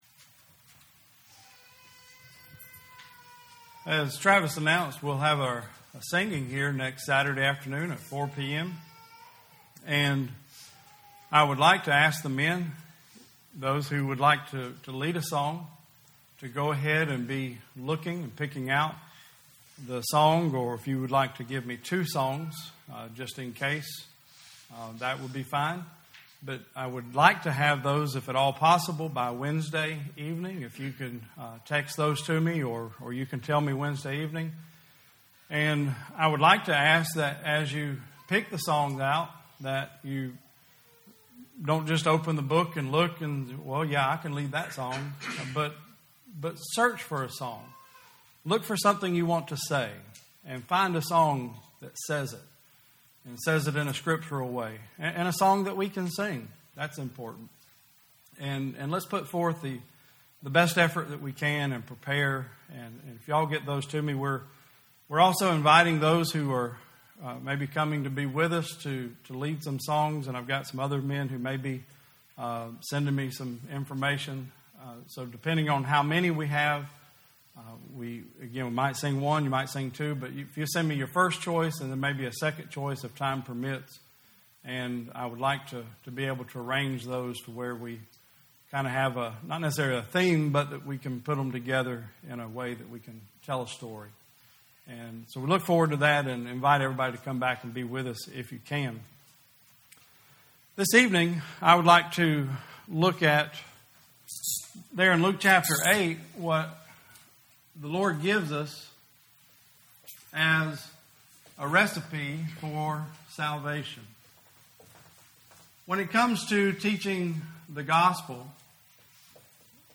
2020 Service Type: Sunday Service Preacher